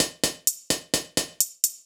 Index of /musicradar/ultimate-hihat-samples/128bpm
UHH_ElectroHatD_128-03.wav